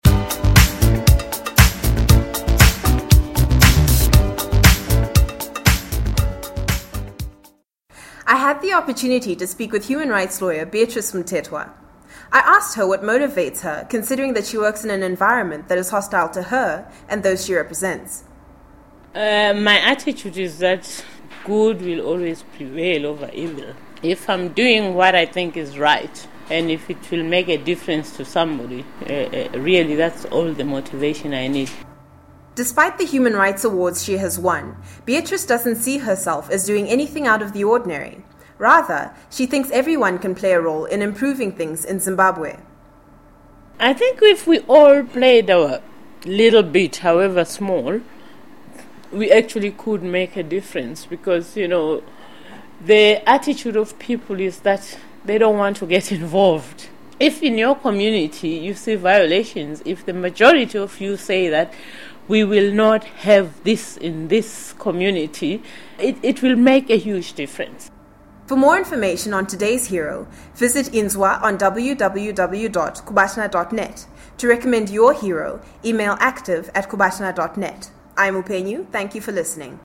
Everyday heroes speaks with human rights lawyer Beatrice Mtetwa
Read the full interview with Beatrice Mtetwa